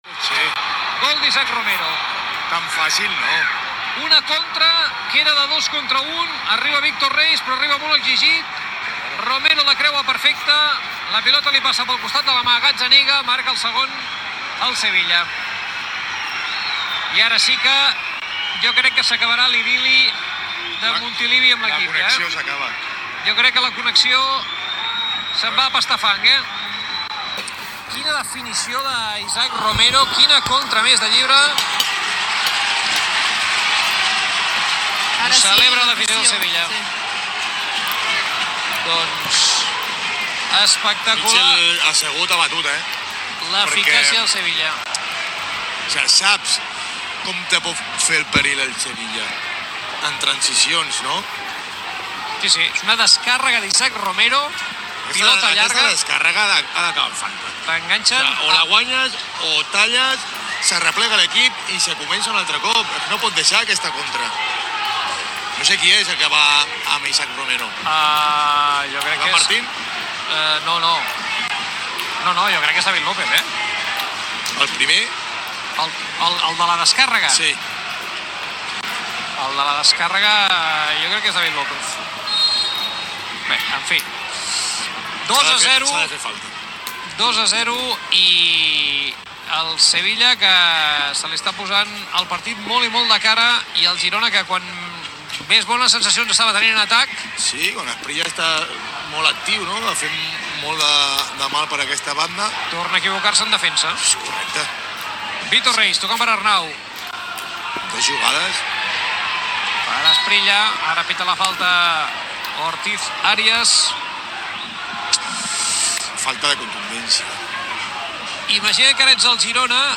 Transmissió del partit de futbol de primera divisió de la lliga masculina entre el Girona i el Sevilla. Segon gol del Sevilla i comentari de la reacció del públic.
Esportiu